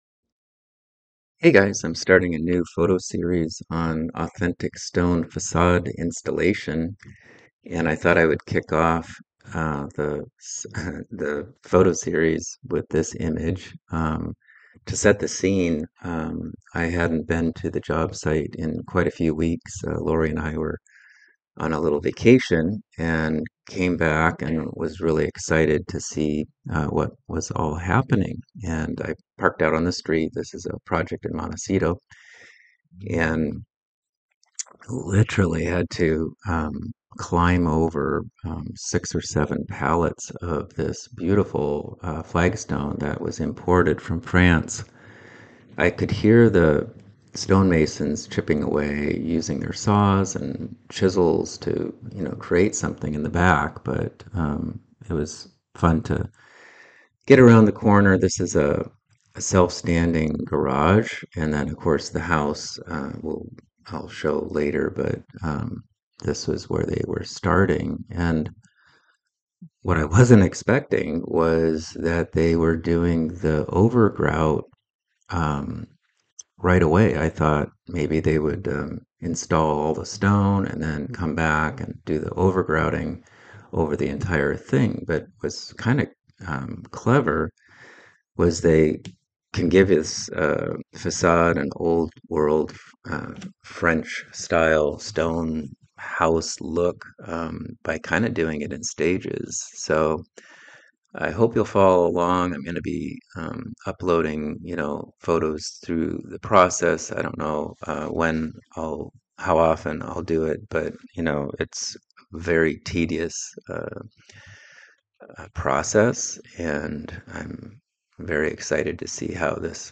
Listen as I walk you through this stone facade job site as you check out the photo